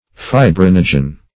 Fibrinogen \Fi*brin"o*gen\, n. [Fibrin + -gen.] (Physiol. Chem.)